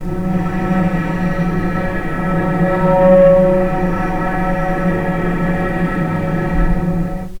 vc_sp-F#3-pp.AIF